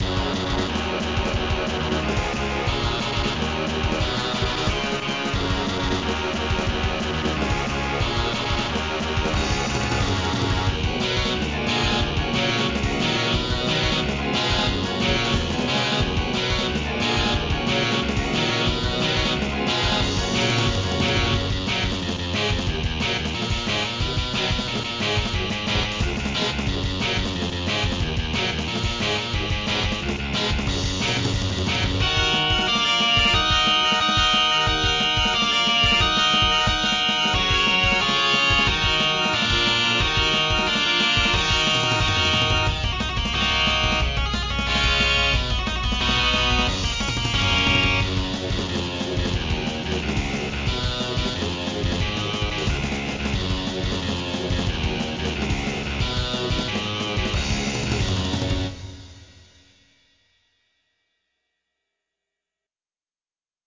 「.」　andante